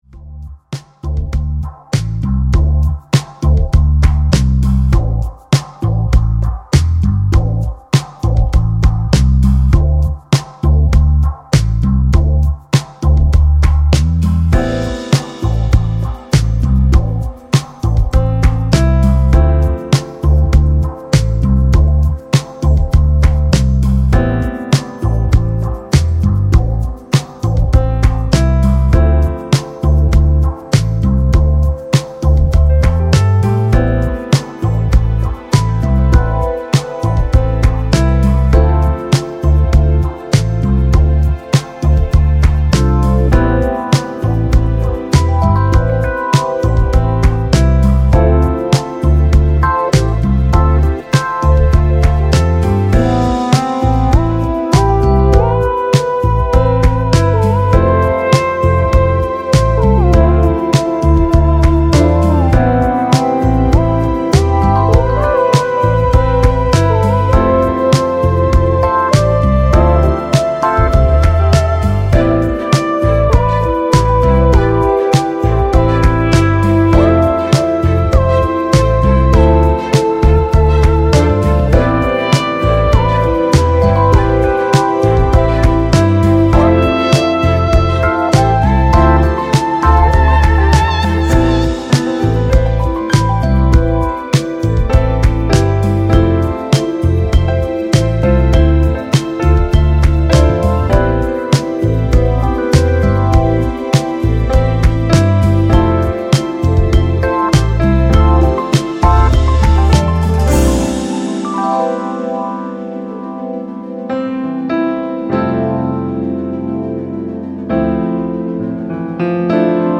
Offering positivity and melancholia in equal measure